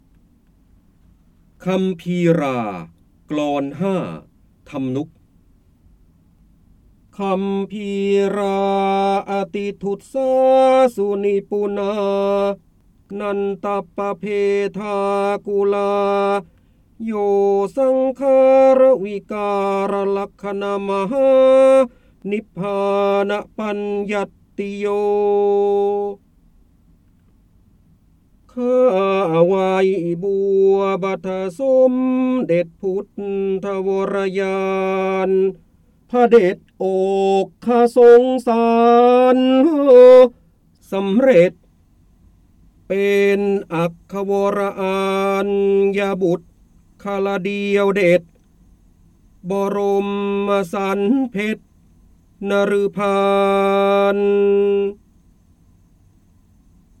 เสียงบรรยายจากหนังสือ จินดามณี (พระโหราธิบดี) คัมภีรา กลอน ๕ ฯ ทำนุกฯ
ลักษณะของสื่อ :   คลิปการเรียนรู้, คลิปเสียง